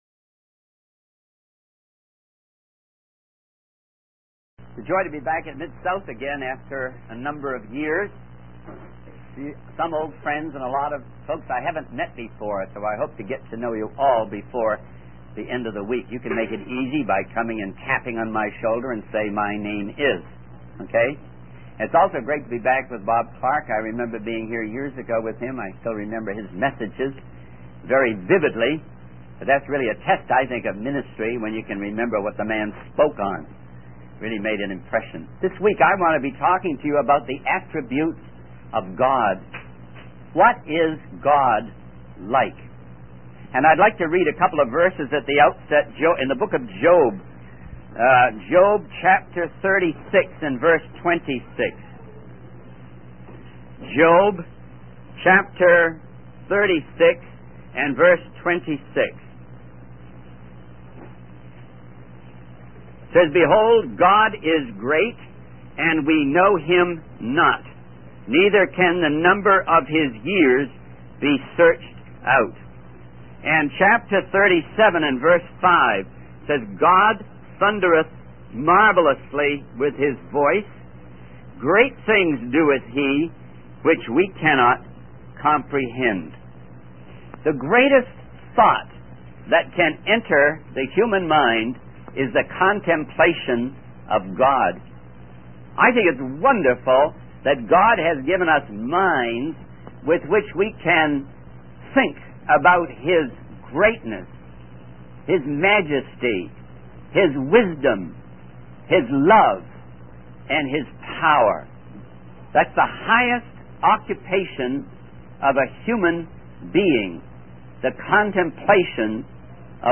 In this sermon, the preacher emphasizes the greatness of God and His ability to behold and count the things in heaven and on earth.